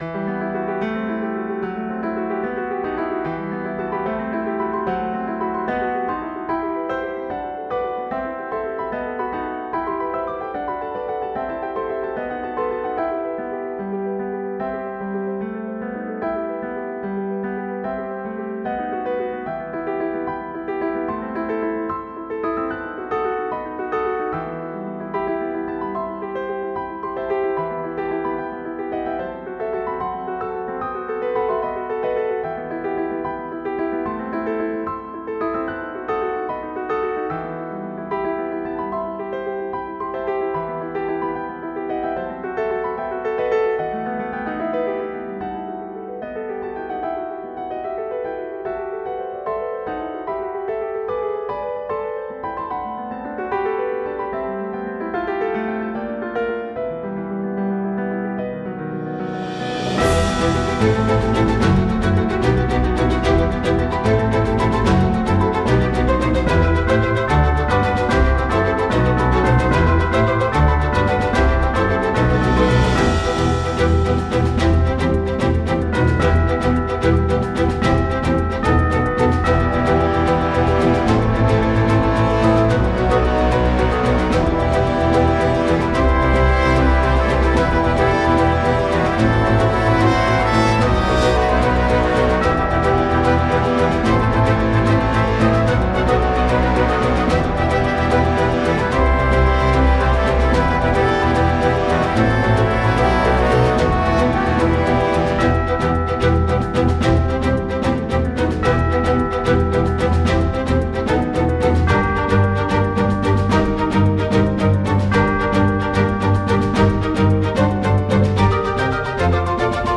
ファンタジー系フリーBGM｜ゲーム・動画・TRPGなどに！
序盤の中ボス感。そんない重すぎない雰囲気だけど、良い感じに緊張感がある。